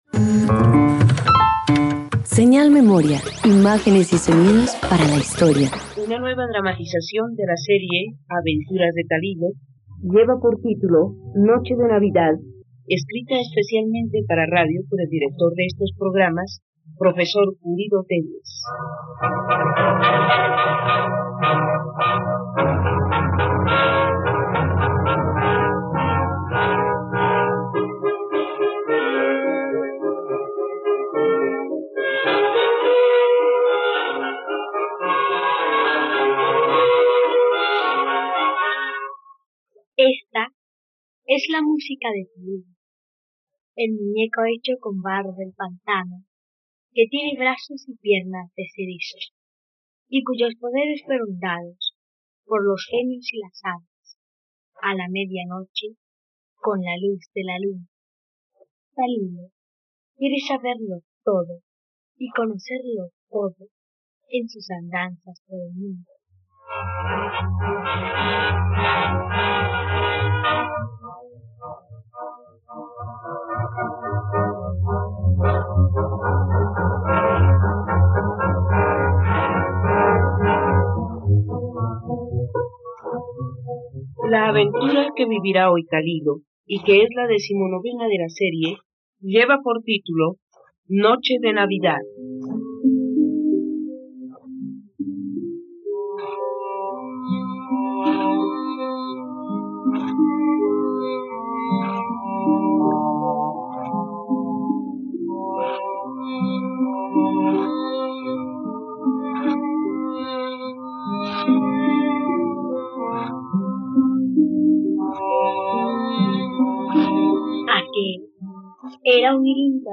Aventuras de Talilo - Radioteatro dominical | RTVCPlay